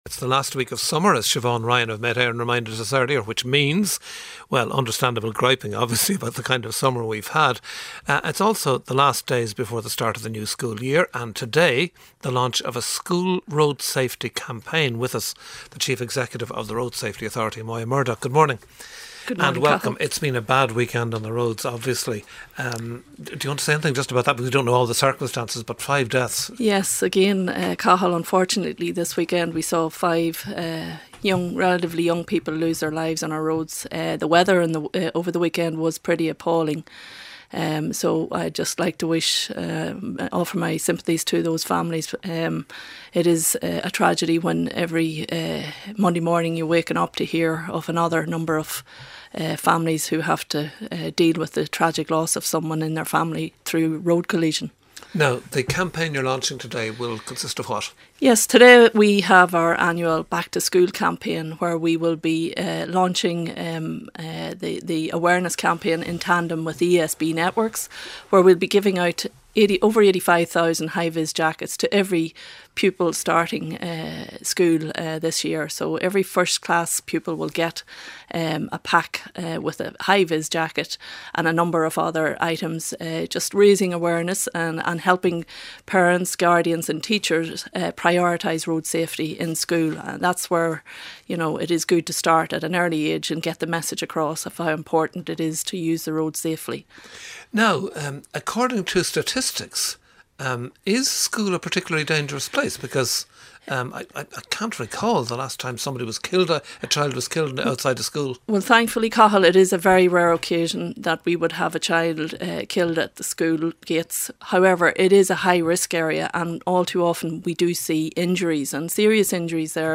interview on rte mi 4 minutes in